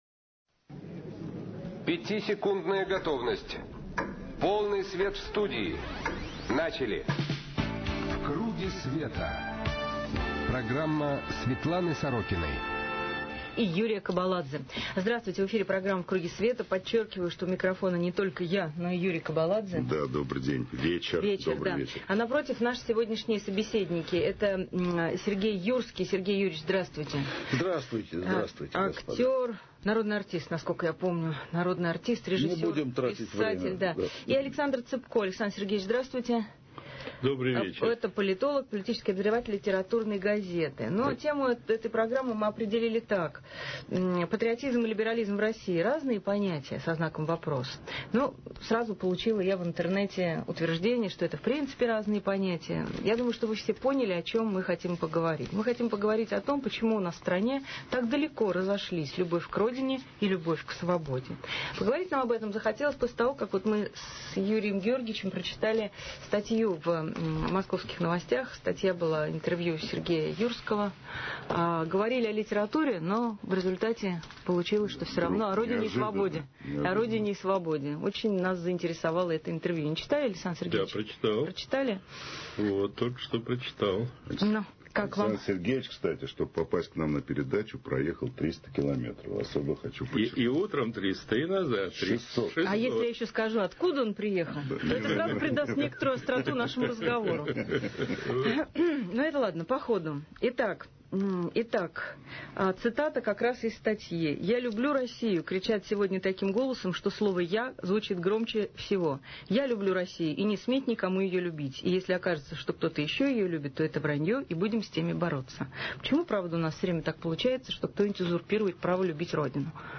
В КРУГЕ СВЕТА программа Светланы Сорокиной на радио «Эхо Москвы» соведущий - Юрий Кобаладзе 05 мая 2007 г. Либерализм и патриотизм: почему в России это разные понятия? Гости – Сергей Юрский, Александр Ципко .